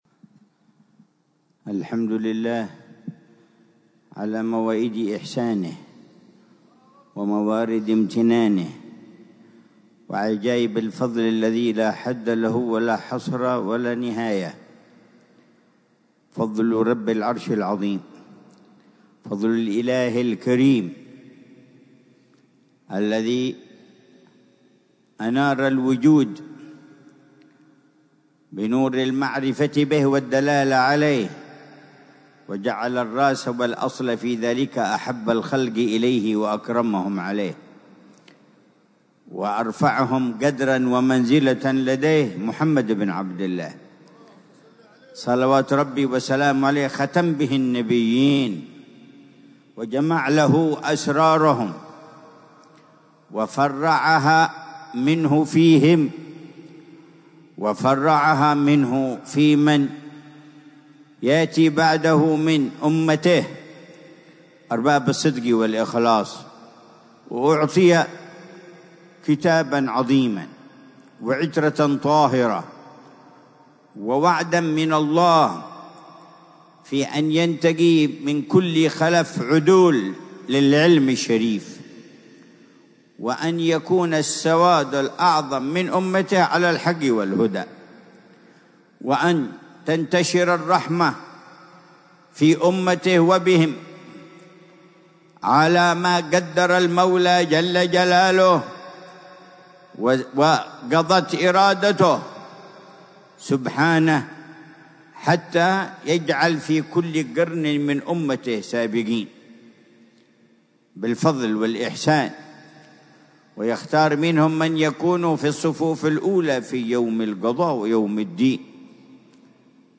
محاضرة العلامة الحبيب عمر بن محمد بن حفيظ ضمن سلسلة إرشادات السلوك، ليلة الجمعة 3 ذو الحجة 1446هـ في دار المصطفى بتريم، بعنوان: